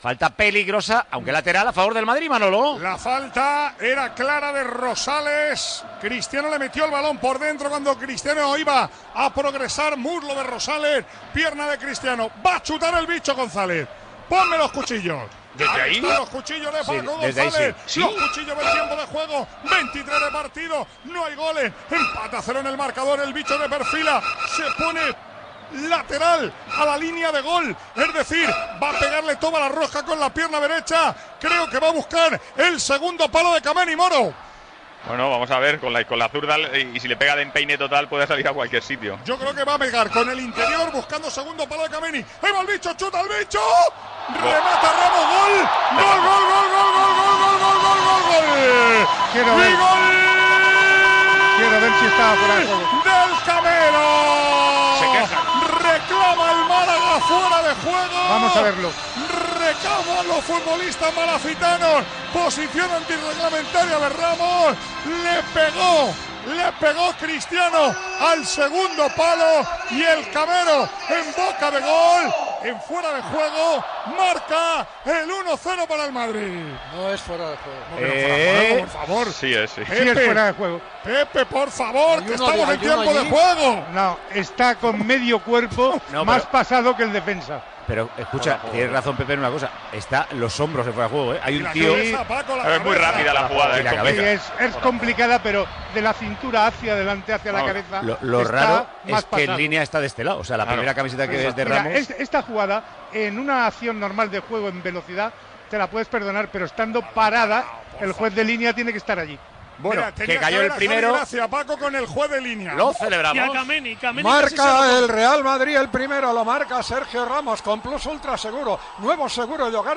Transmissió del partit de la lliga de futbol masculí entre el Real Madrid i el Málaga.
Narració del llançament de la falta de Cristiano Ronaldo i del gol de Sergio Ramos. Publicitat, reacció a la banqueta, comentaris i narració de la següent jugada.
Esportiu